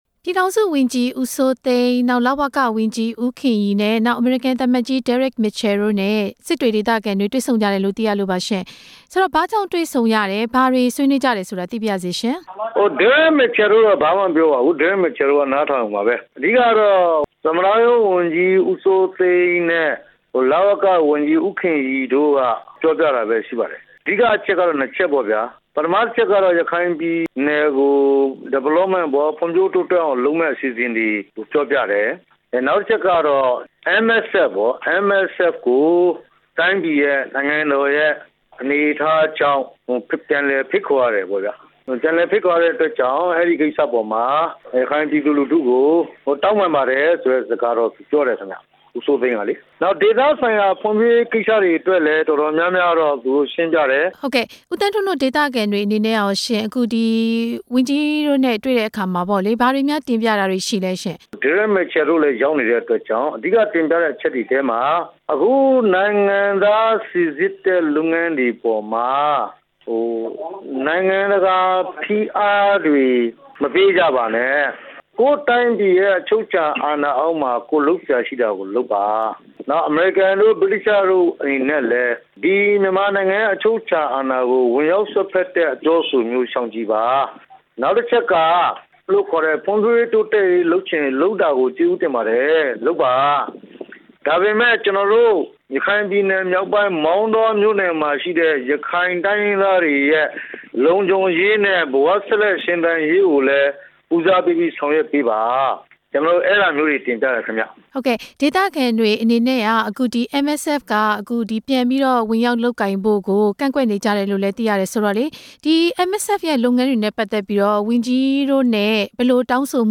အစိုးရဝန်ကြီးတွေနဲ့ ရခိုင် အရပ်ဘက်အဖွဲ့အစည်းတွေ တွေ့ဆုံပွဲ မေးမြန်းချက်